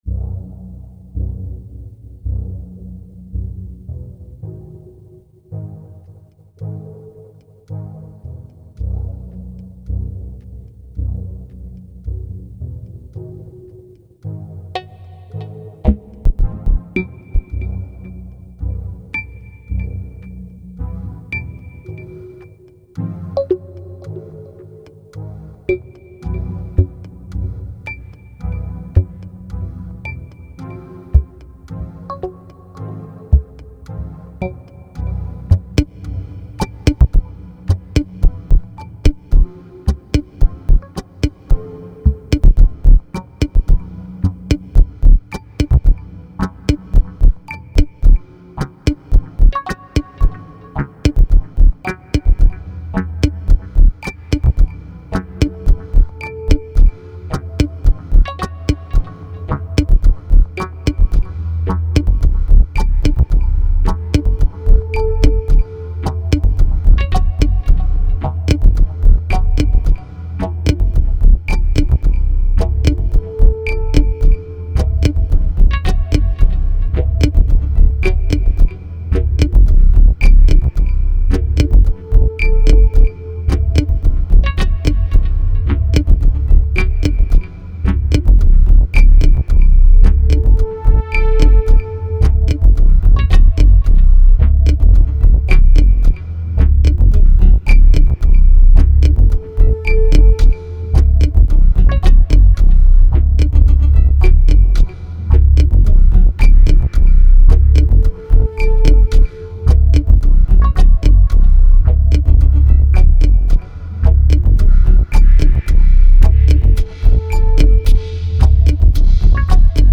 Tight and progressive, it seems to flow out more naturally.
2350📈 - -44%🤔 - 110BPM🔊 - 2008-11-01📅 - -361🌟